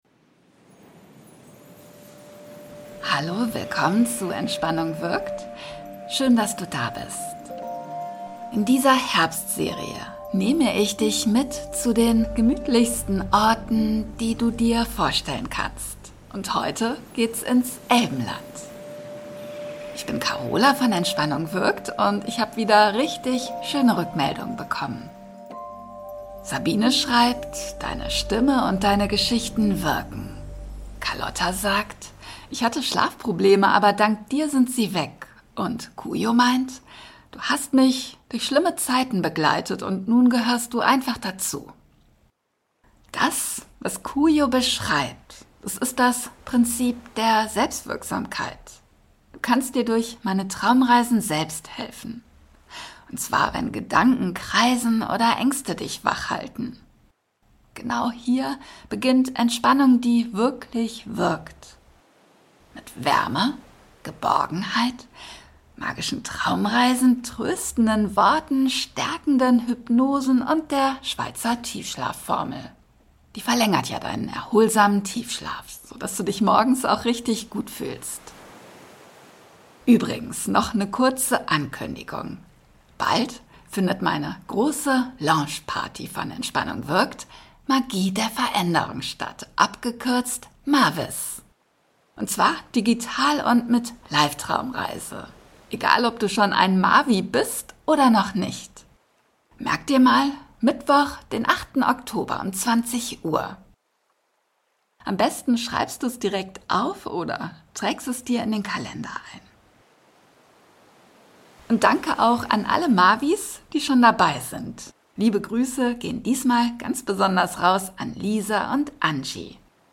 In dieser geführten Traumreise unternimmst du einen entspannten Spaziergang durch eine wunderschöne, friedliche Landschaft, die Ruhe und Gelassenheit schenkt.